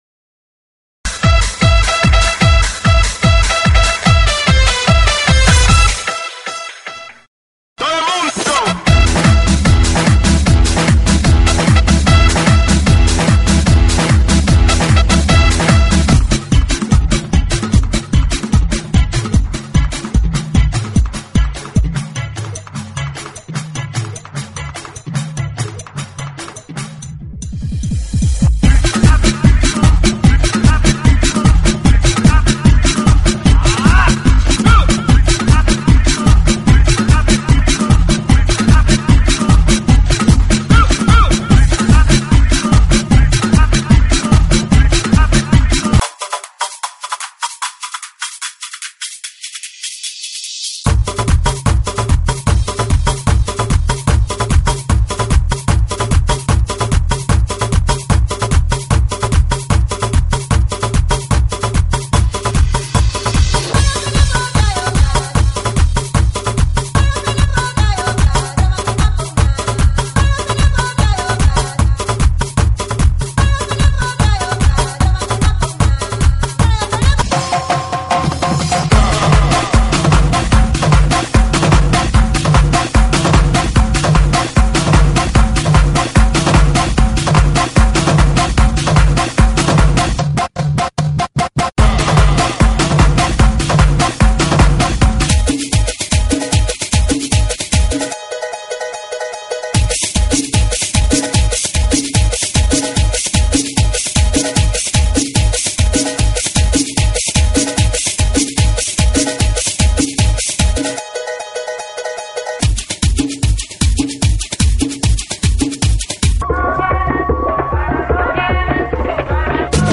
GENERO: TRIBAL – HOUSE
TRIBAL HOUSE,